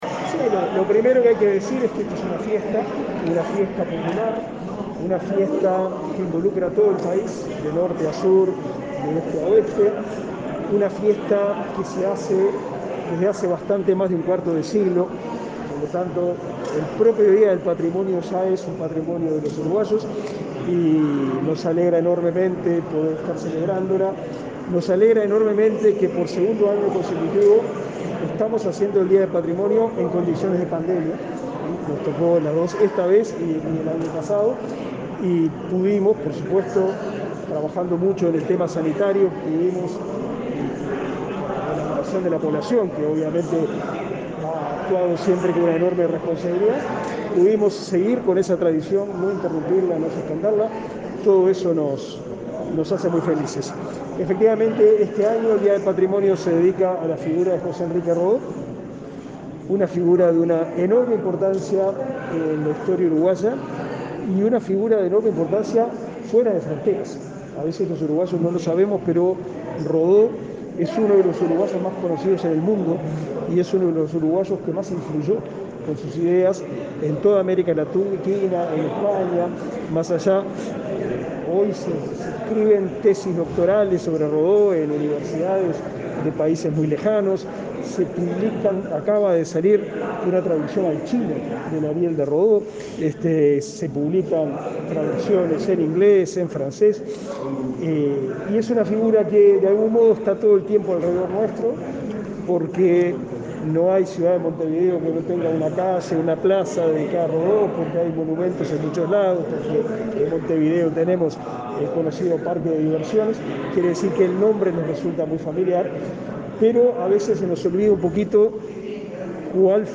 Declaraciones a la prensa del ministro Pablo da Silveira
Declaraciones a la prensa del ministro Pablo da Silveira 02/10/2021 Compartir Facebook Twitter Copiar enlace WhatsApp LinkedIn El ministro de Educación y Cultura, Pablo da Silveira, participó de la celebración del Día de Patromonio, realizada en el Museo de Artes Visuales, y, luego, dialogó con la prensa.